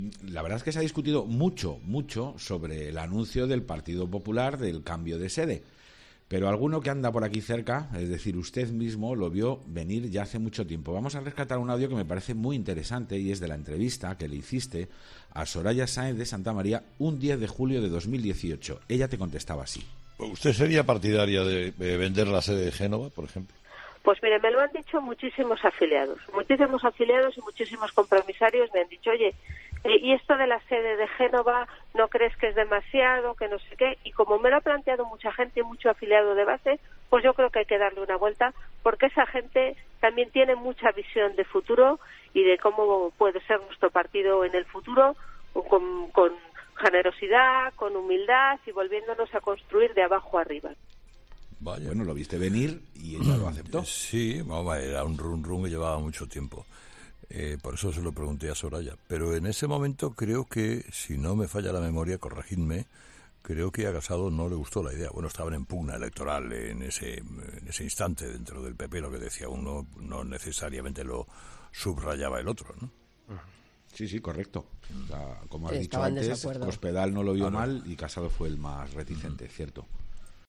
Entrevistado: "Soraya Sáenz de Santamaría"
En una entrevista realizada en 'Herrera en COPE' en julio de 2018, la exvicepresidenta advertía que sería una forma de "volvernos a construir de abajo a arriba"